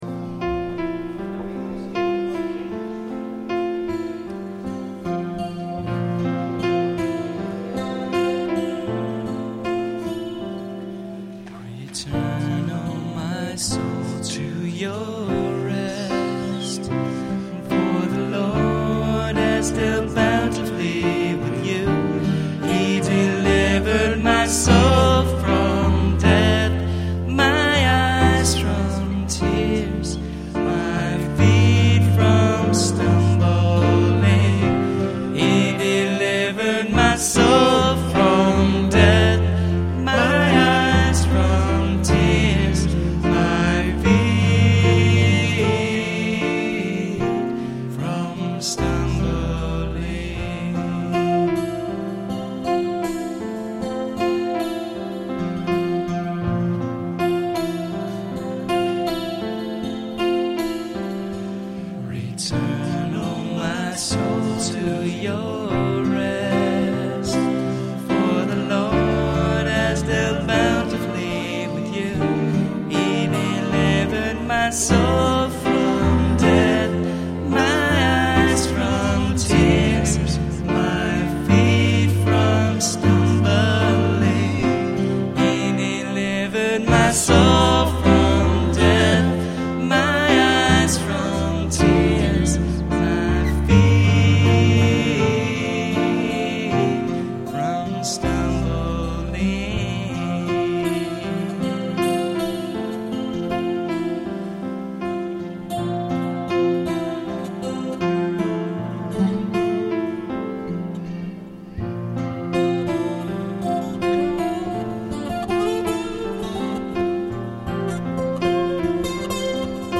MP3 live at Faith, extended Communion version